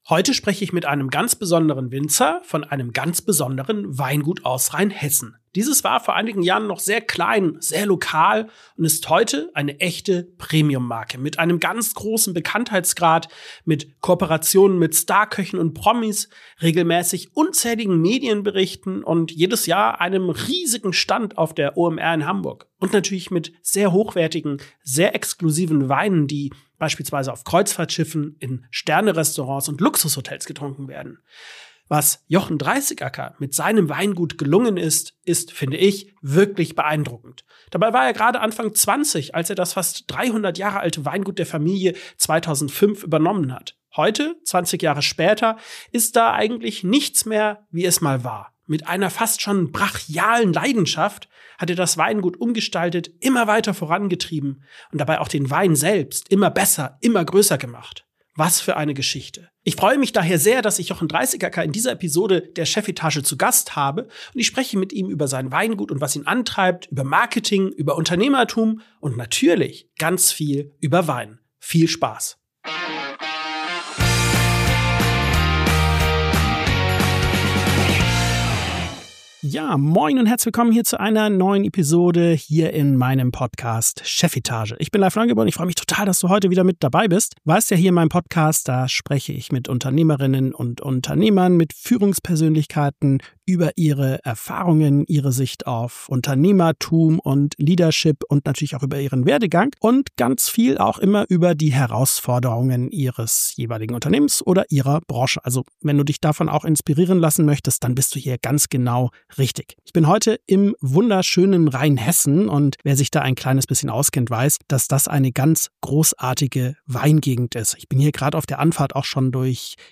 CEOs, Unternehmer und Führungskräfte im Gespräch Podcast